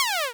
laser1.wav